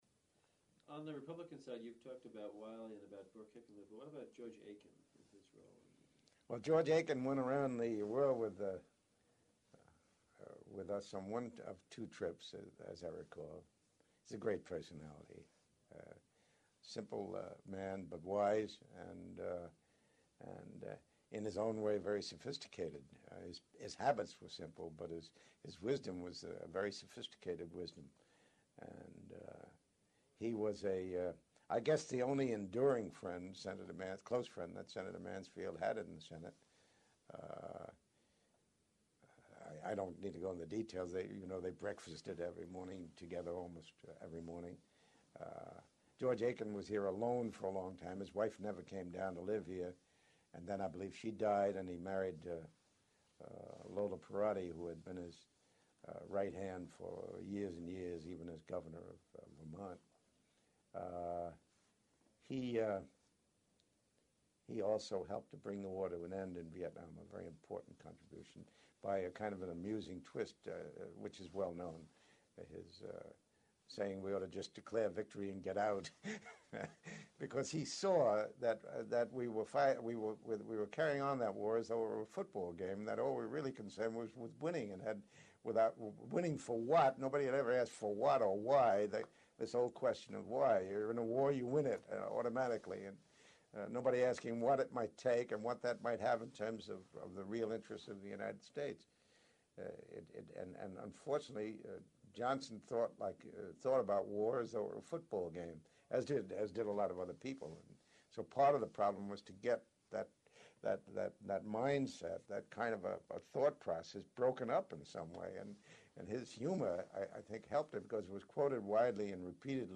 Oral History Project
The following excerpt from the oral history interview with former secretary of the Senate Francis Valeo recounts the wit and wisdom of Vermont senator George Aiken.